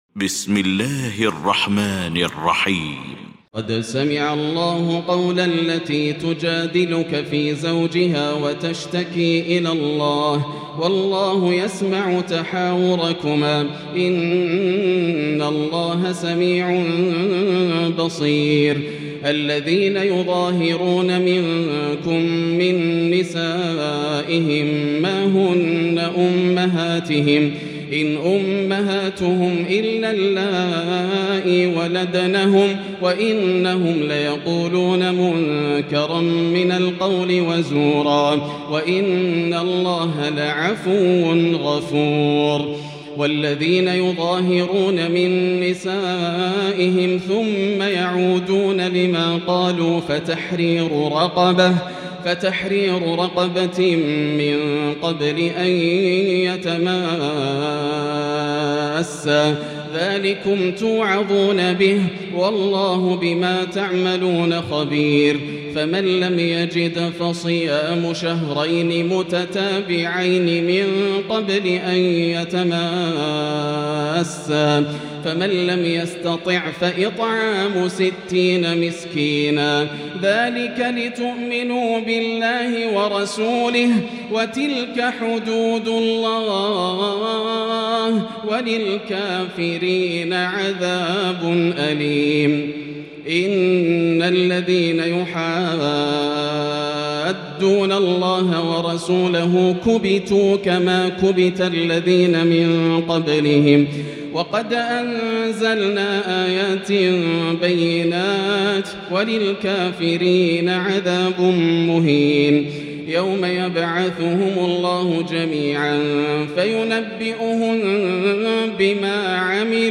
المكان: المسجد الحرام الشيخ: فضيلة الشيخ ياسر الدوسري فضيلة الشيخ ياسر الدوسري المجادلة